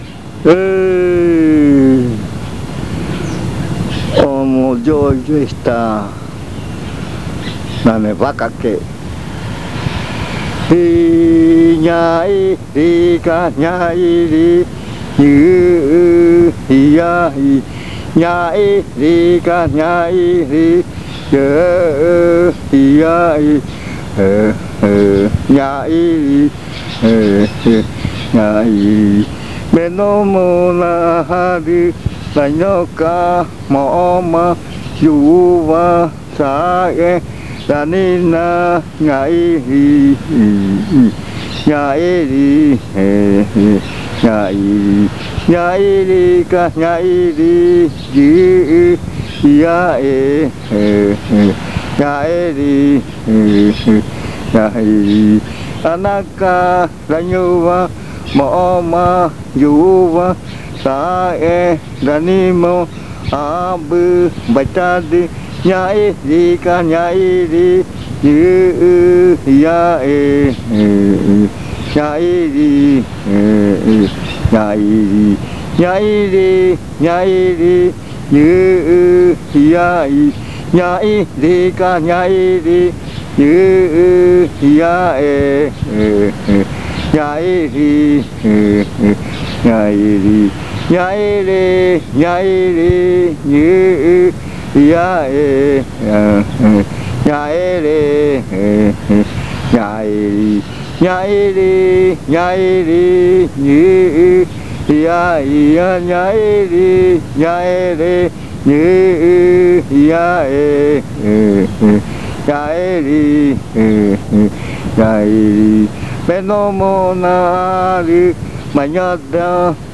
Canto satírico. Dice la canción: solo se la pasa sentado.
Satirical chant.